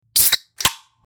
Soda Can Fizz
Soda_can_fizz.mp3